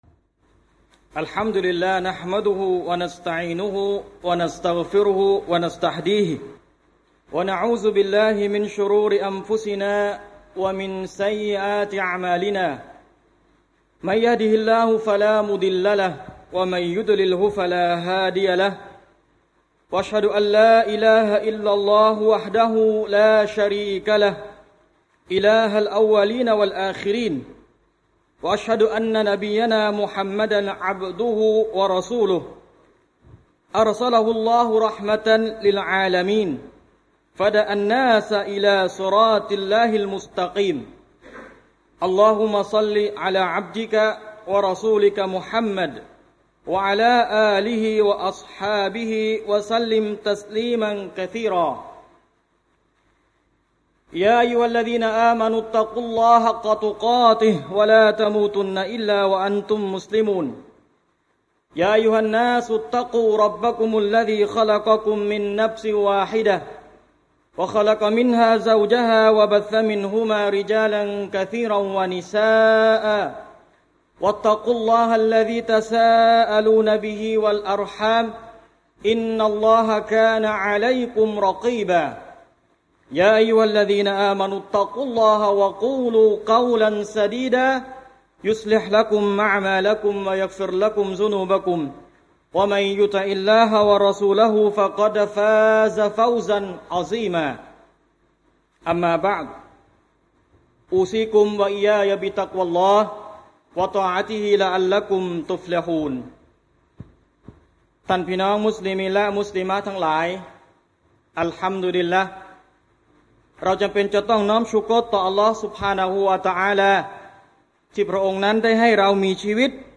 คุตบะห์วันศุกร์ที่ 31 กรกฎาคม 2552